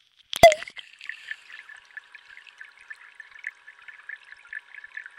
水听器 " 水听器池落
描述：从Harlaxton庄园的池塘录制的水听器。
Tag: 池塘 水听器